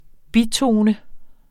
Udtale [ ˈbiˌtoːnə ]